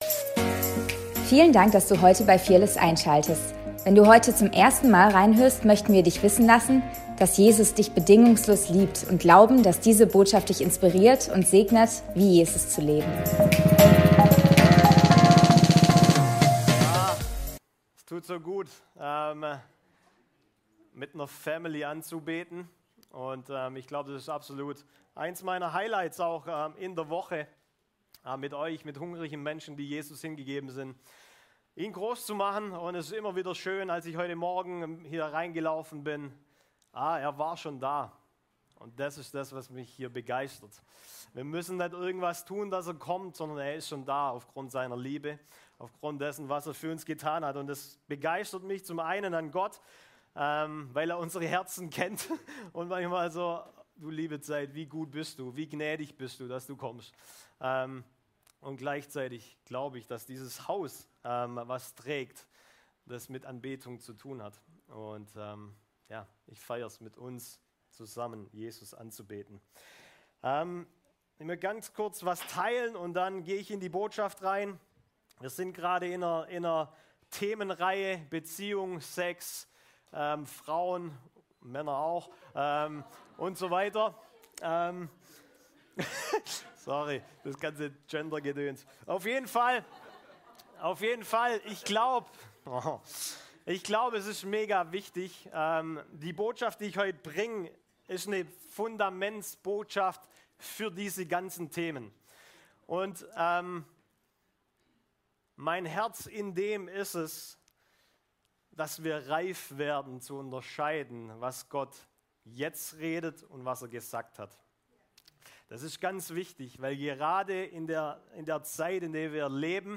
Predigt vom 14.07.2024